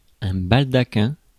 Ääntäminen
Synonyymit ciel de lit Ääntäminen France: IPA: [bal.da.kɛ̃] Haettu sana löytyi näillä lähdekielillä: ranska Käännös Substantiivit 1. балдахин {m} Suku: m .